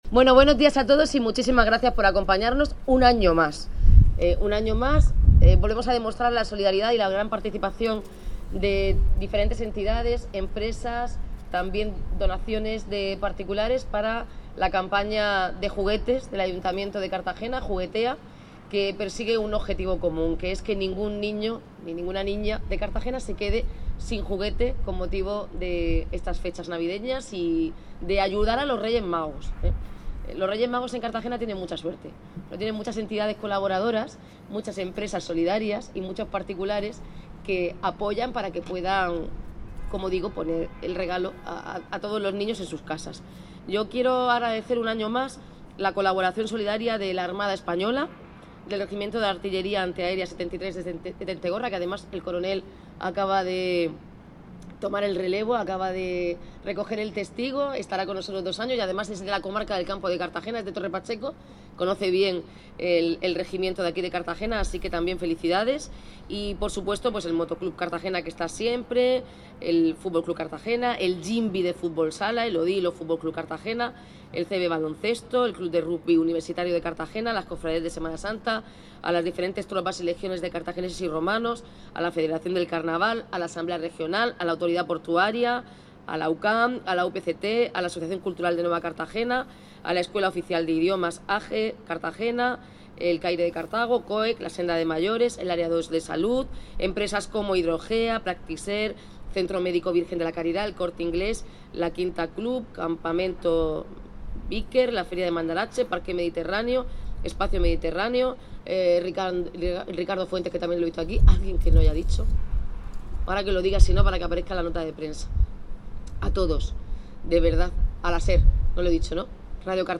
Enlace a Declaraciones de la alcaldesa, Noelia Arroyo, en el cierre de la campaña Juguetea